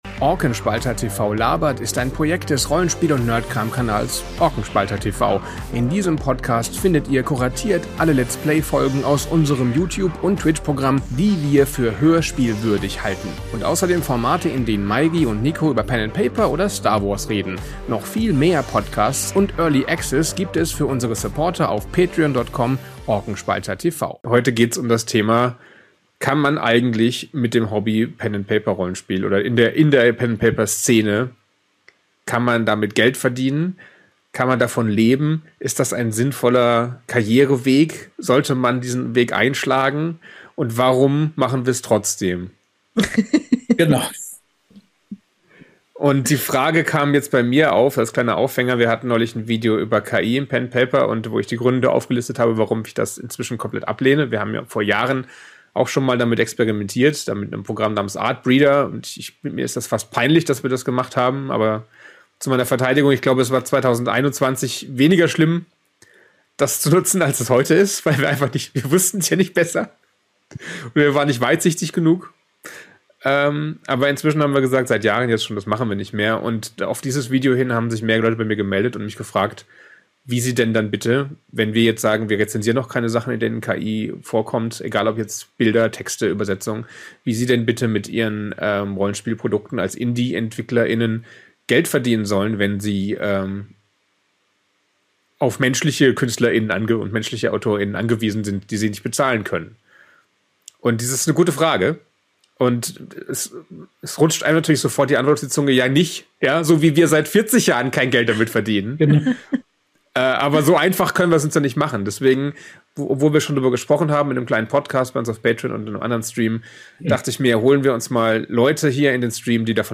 Talk: Kann man mit Pen and Paper in Deutschland Geld verdienen? ~ Orkenspalter TV labert Podcast